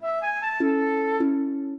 minuet1-12.wav